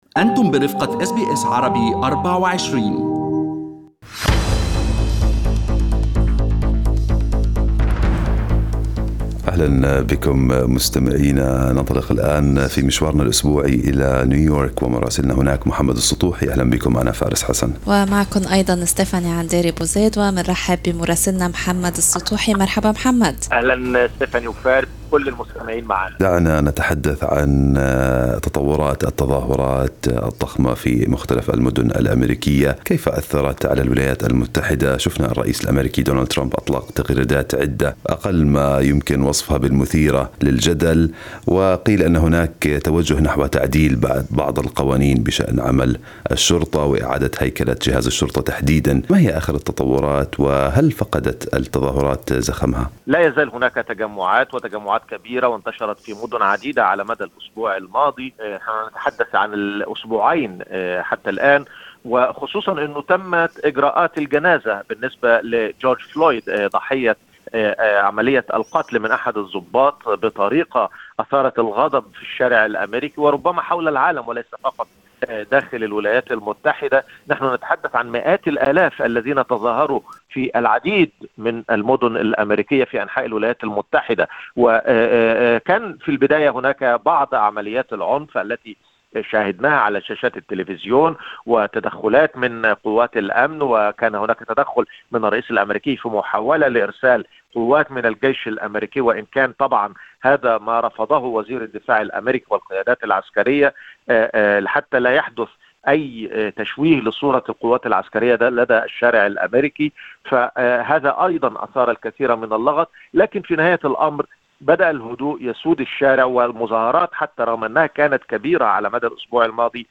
من مراسلينا: أخبار الولايات المتحدة الأمريكية 11/06/2020
نتابع مع مراسلنا في نيويورك